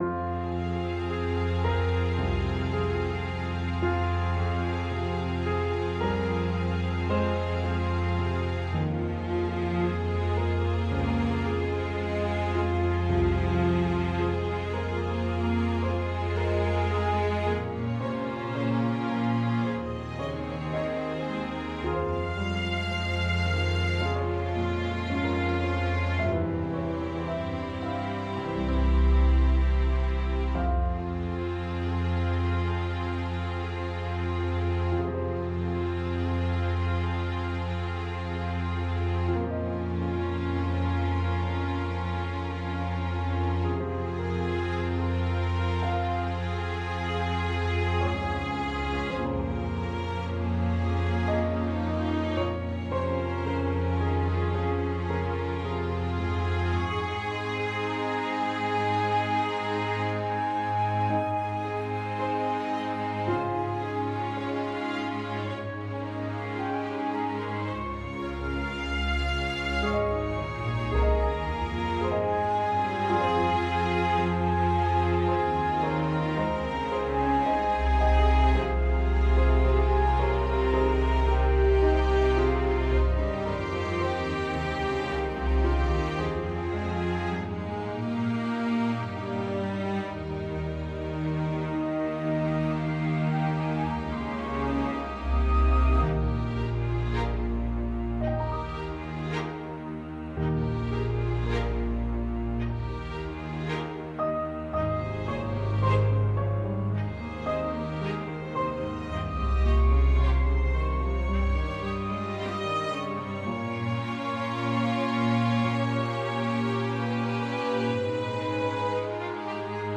🎵 Trilha Backing Track